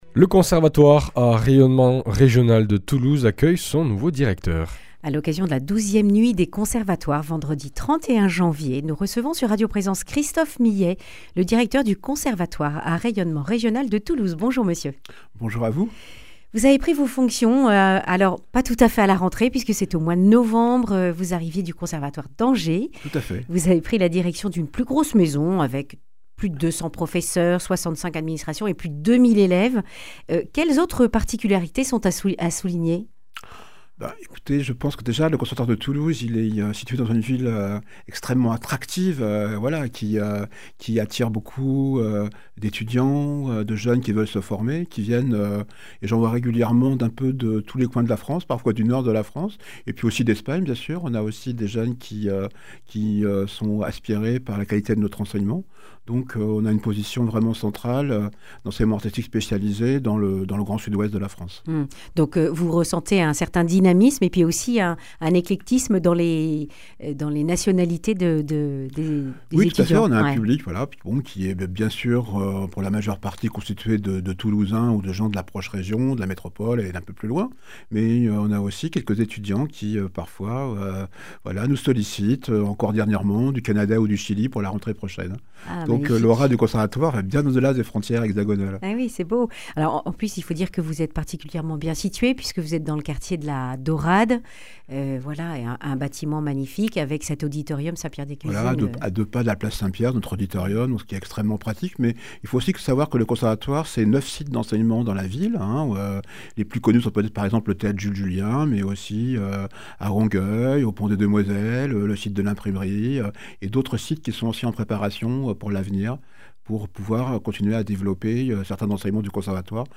Accueil \ Emissions \ Information \ Régionale \ Le grand entretien \ La Nuit des conservatoires, un feu d’artifice de créations artistiques !